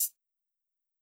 Closed Hat (Hyyerr).wav